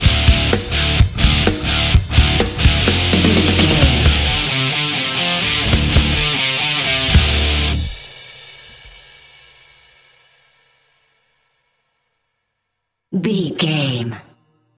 Phrygian
drums
electric guitar
bass guitar
hard rock
aggressive
energetic
intense
nu metal
alternative metal